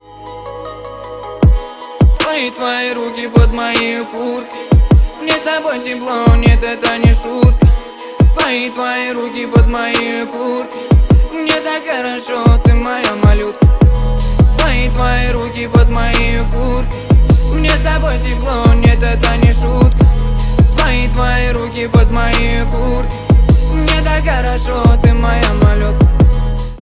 мужской вокал
лирика
спокойные
басы